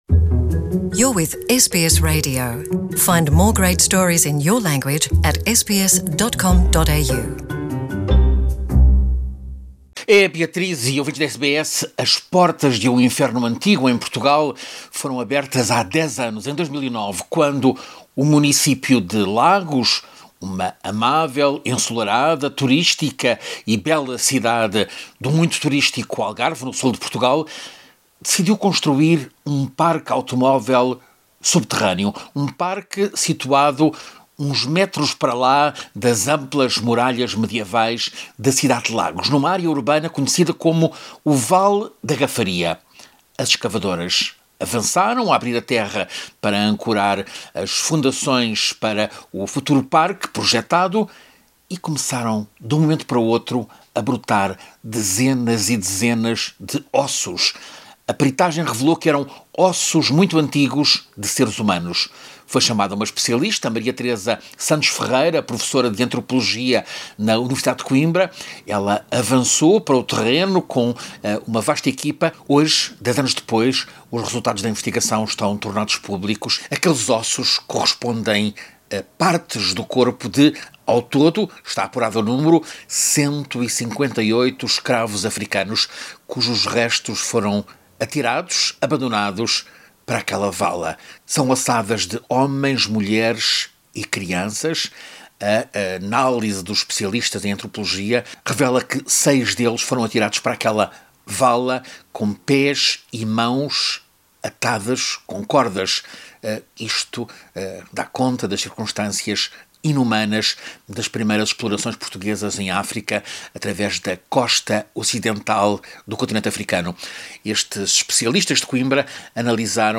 O estudo científico revelou que se trata de ossos de 158 escravos africanos atirados para aquela vala, no tempo dos séculos XV e XVI em que Lagos foi o principal mercado de escravos no sul da Europa. Ouça a reportagem do correspondente da SBS em português